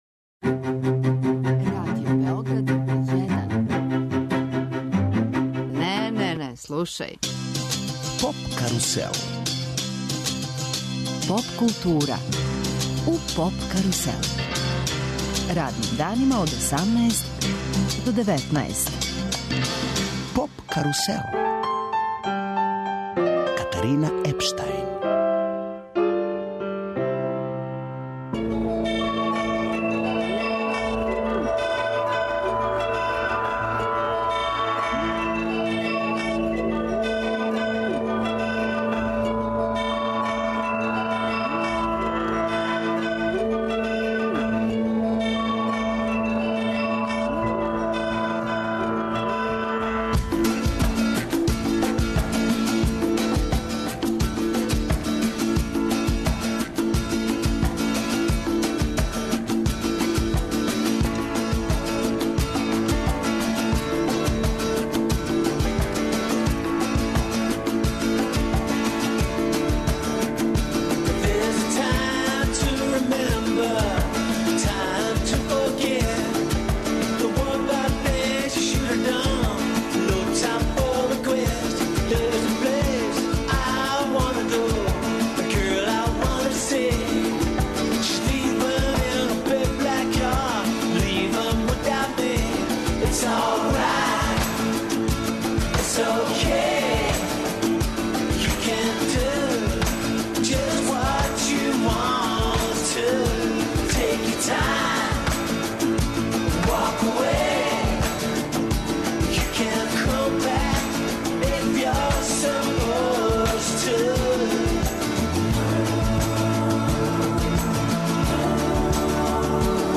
Гости у студију су чланови једног од највећих регионалних састава, Хладно пиво. Разговараћемо и о Lovefestu, где ће и они бити један од учесника.